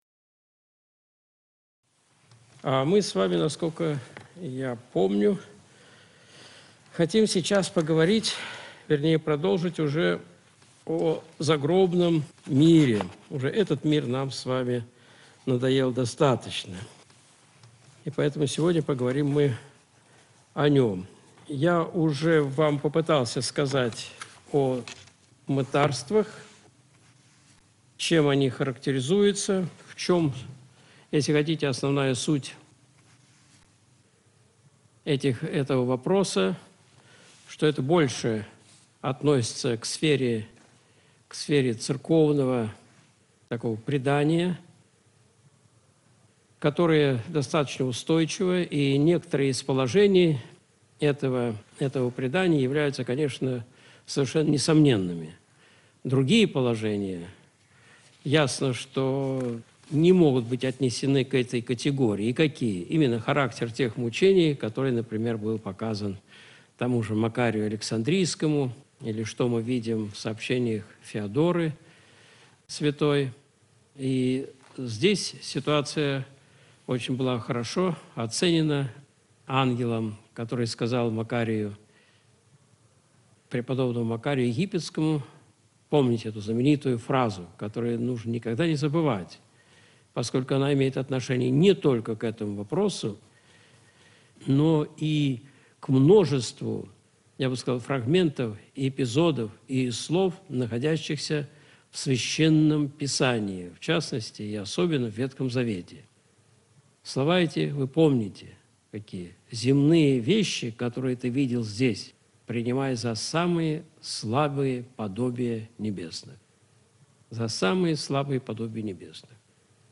Видеолекции протоиерея Алексея Осипова